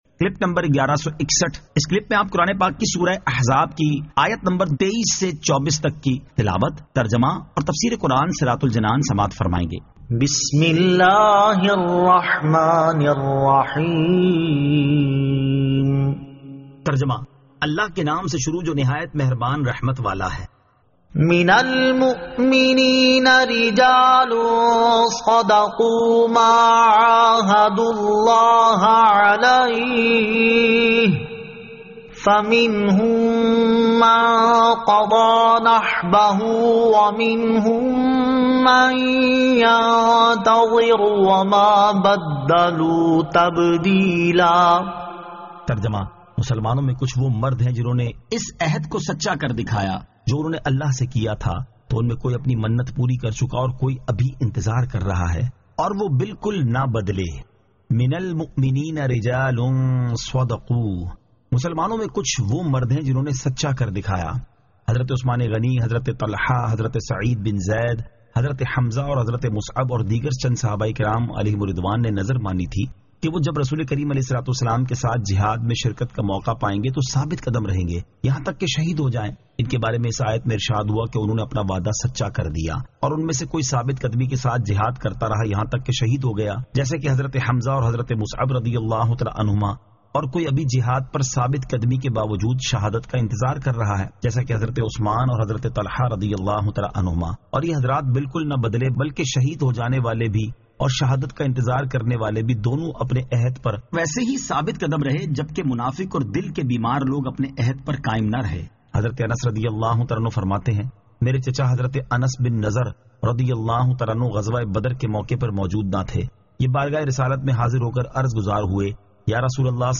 Surah Al-Ahzab 23 To 24 Tilawat , Tarjama , Tafseer